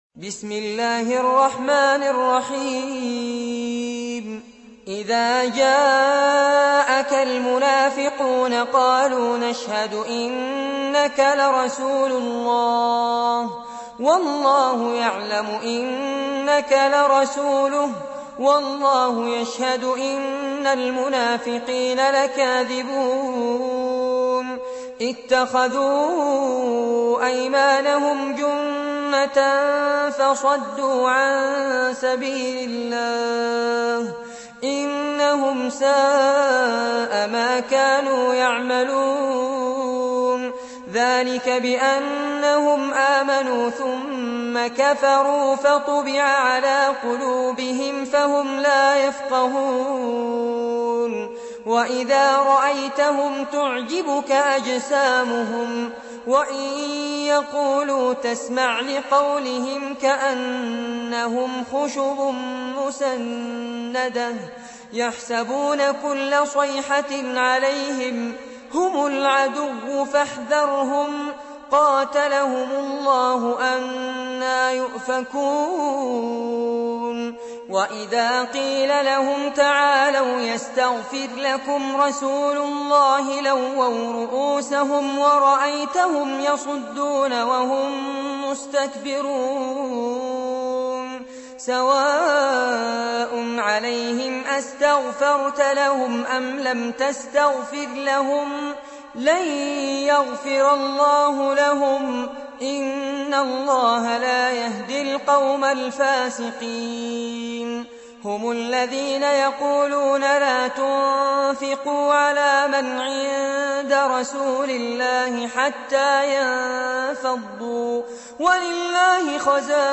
Récitation par Fares Abbad